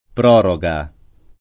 pr0roga] s. f. — oggi rara la var. prorogazione — nel linguaggio giur., anche prorogatio [lat. prorog#ZZLo] (es.: prorogatio imperii [prorog#ZZLo imp$ri-i], nel diritto romano) — non prologa